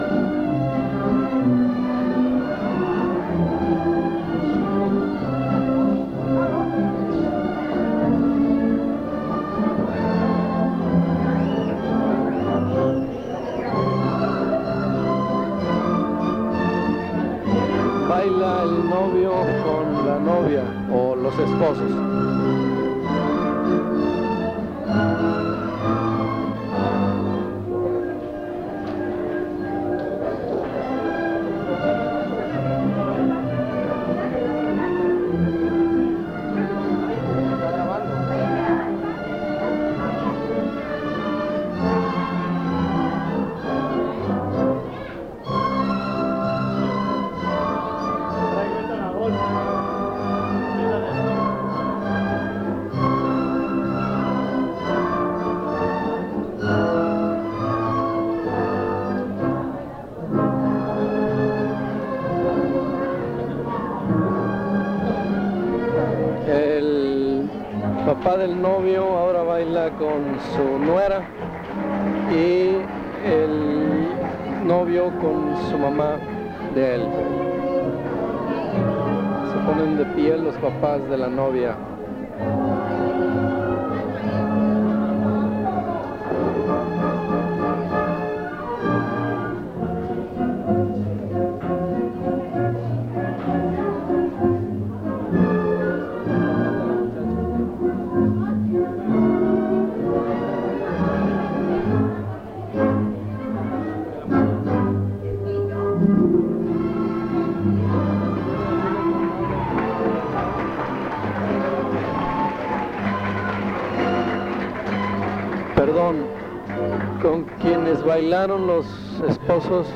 Fiesta de La Candelaria: investigación previa